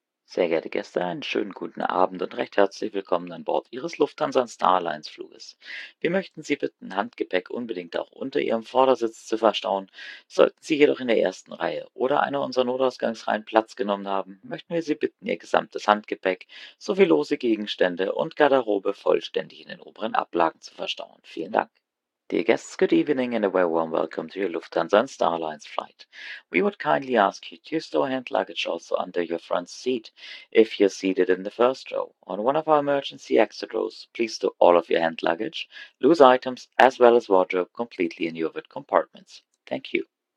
BoardingWelcome[Evening].ogg